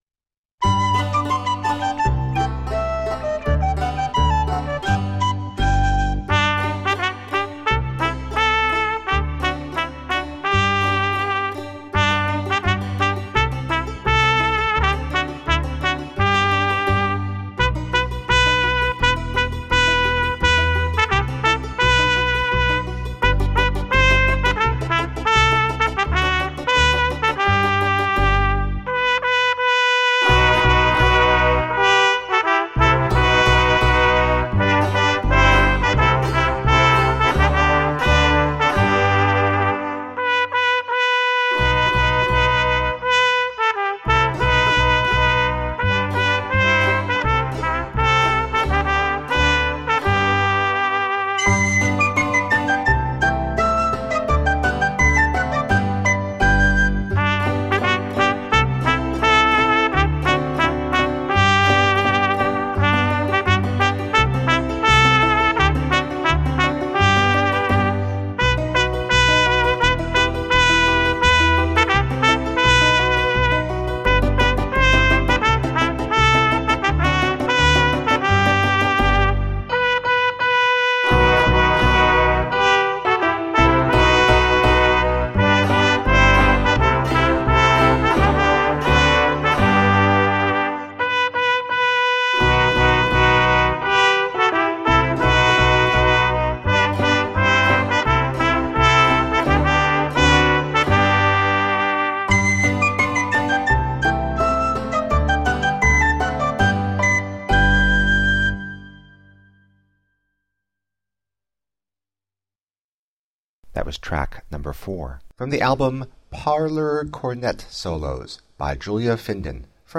Delightful nostalgic melodies for cornet solo.